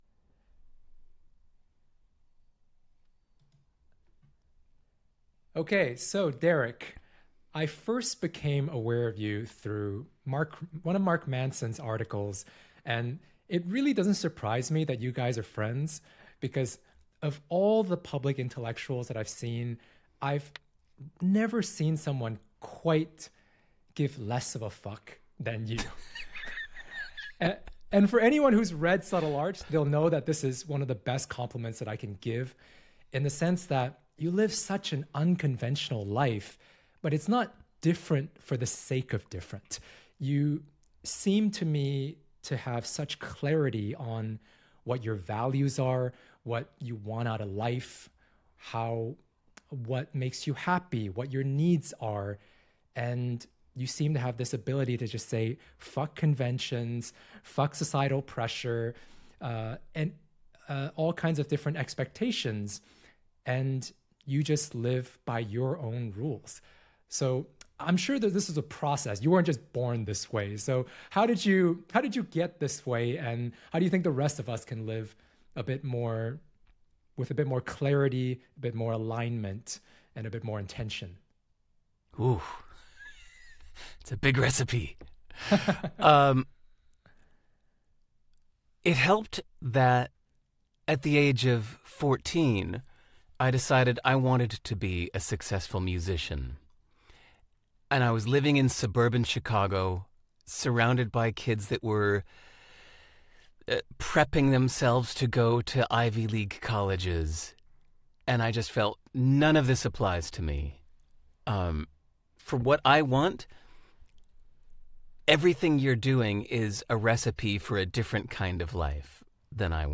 Less Clueless interview
Great conversation around reframing beliefs and limitations. Cut off at the end but that's OK because we'll probably do a Part 2.